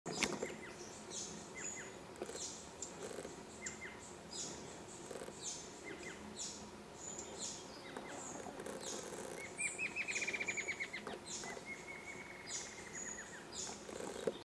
Rufous-margined Antwren (Herpsilochmus rufimarginatus)
Life Stage: Adult
Location or protected area: Parque Provincial Salto Encantado
Condition: Wild
Certainty: Recorded vocal
MVI_6783-tiluchi-ala-rojiza.mp3